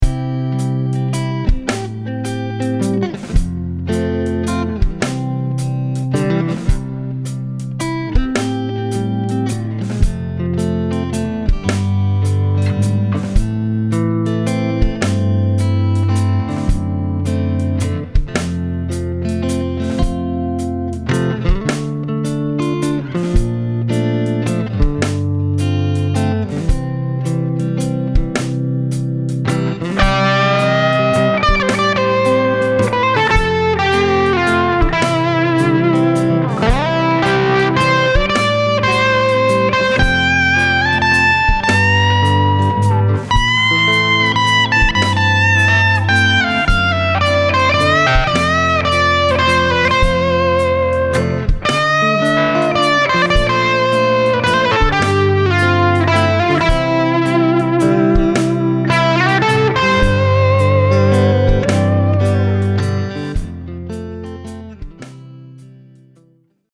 I came up with a song idea this morning, and after just quickly laying down a rhythm and test solo track, I’m SO loving this amp.
For the rhythm track, I’m playing in single coil mode in the neck position, and the lead is played in humbucker mode in the bridge position. For the clean, I’m playing with the clean channel with the Master cranked and Gain about 9am so the signal is absolutely clean. For the lead, I’m playing the lead channel, with the Master at 10 am, and the Gain at 3pm.
And imagine that these tracks were recorded with the DI!!!
smooth.mp3